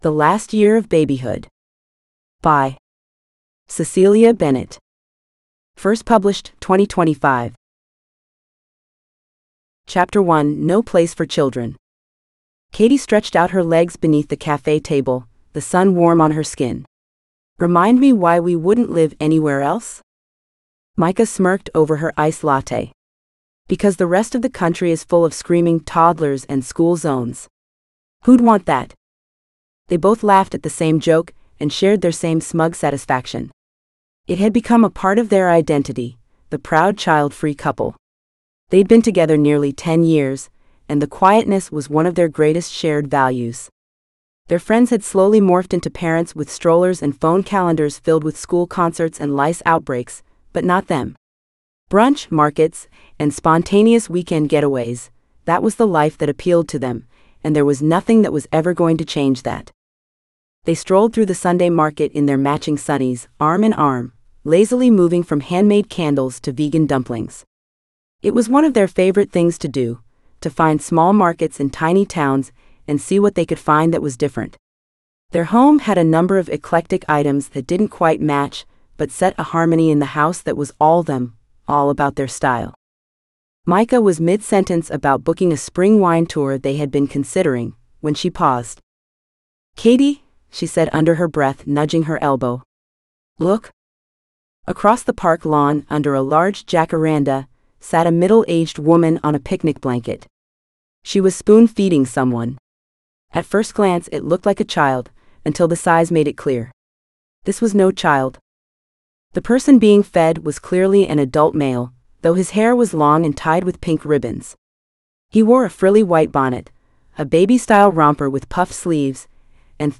The Last Year Of Babyhood (AUDIOBOOK – female): $US2.99